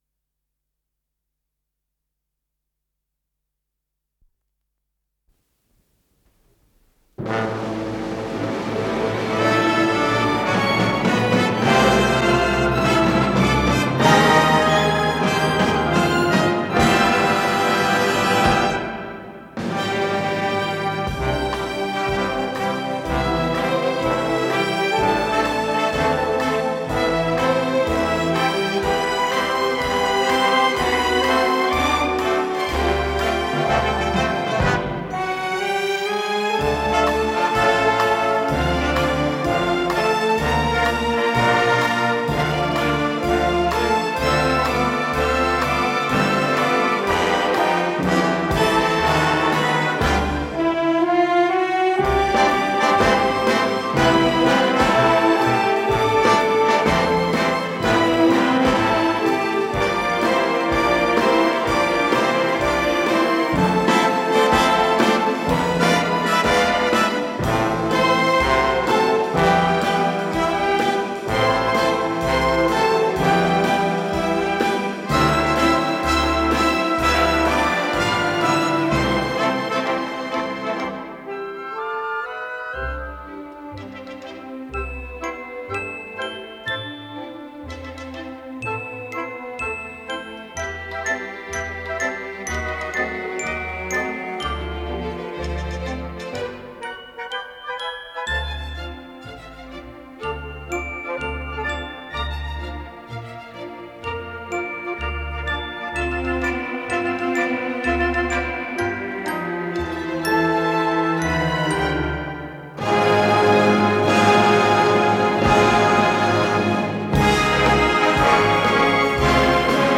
ПодзаголовокПьеса для эстрадно-симфонического оркестра
ВариантДубль моно